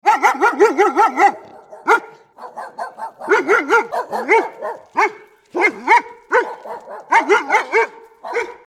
Big Dog Barking Sound Effect Download: Instant Soundboard Button
Dog Barking Sound37 views